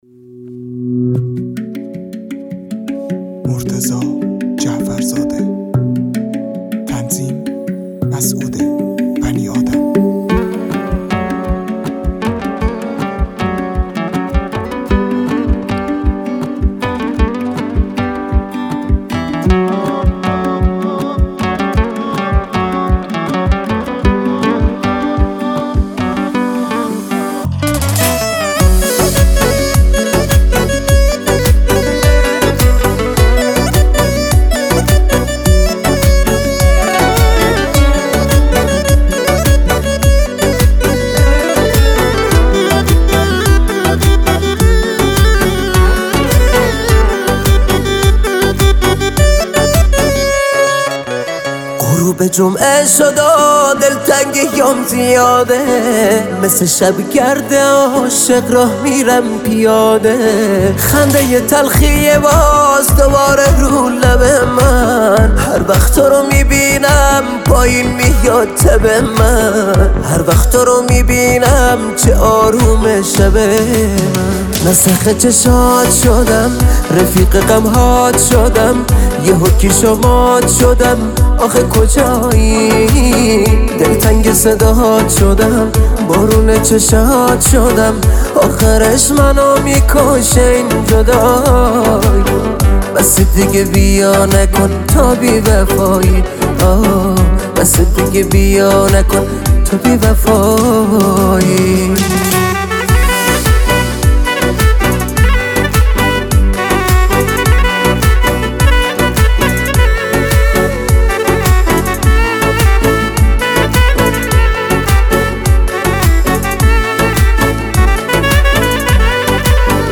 عالییی ترکوندیی صدات چه سوخته است